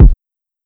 Kick (Uptown).wav